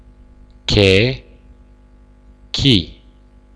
Voiced Sounds of the Romanian Language
Consonants - Speaker #6
che chi